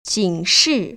警示[jǐngshì]